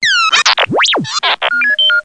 Звуки дроида R2D2 из звёздных войн в mp3 формате